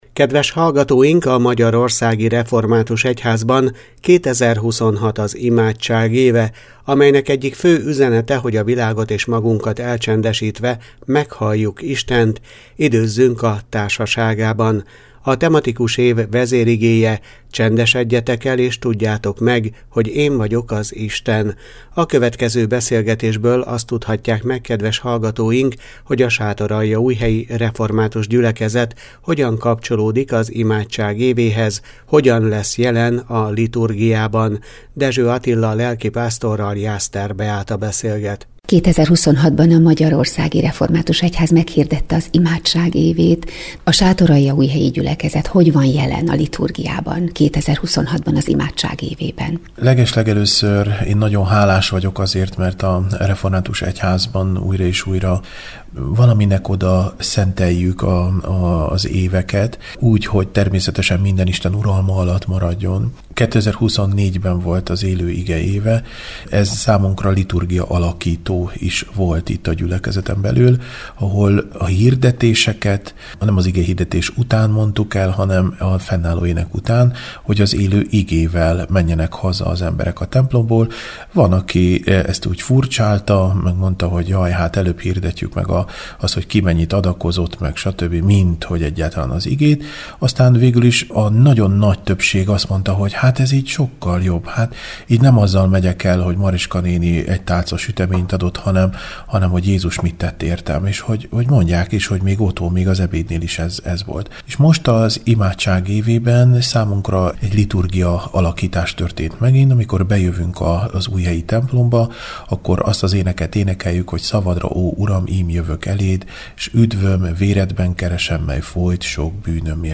A következő beszélgetésből azt tudhatják meg kedves hallgatóink, hogy a sátoraljaújhelyi református gyülekezet hogyan kapcsolódik az imádság évéhez, hogyan lesz jelen a liturgiában.